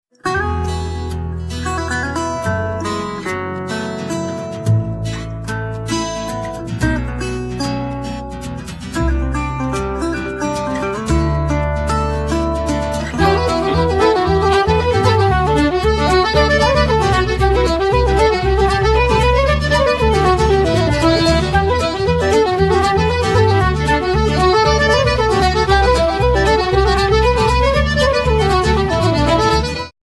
Fiddle
Accordion
Double/Fretless Bass